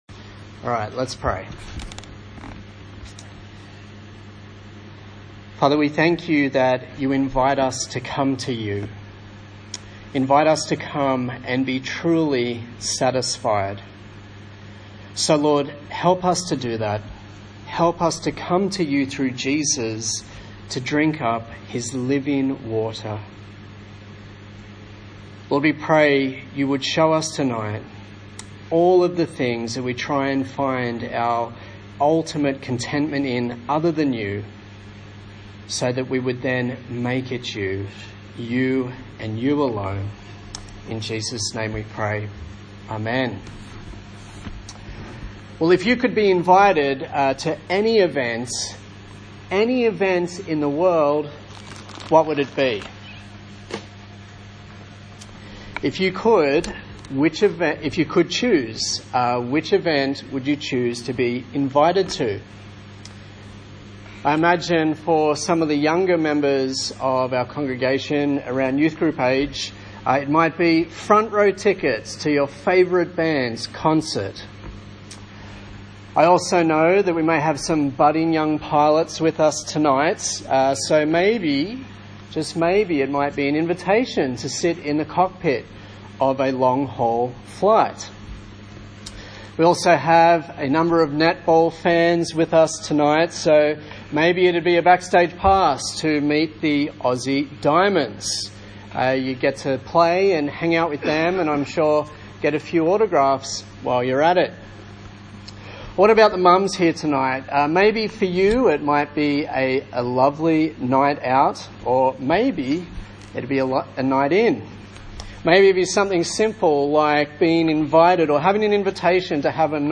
Seek Preacher